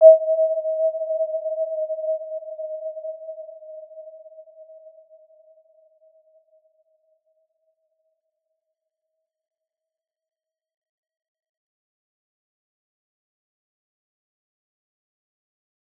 Warm-Bounce-E5-p.wav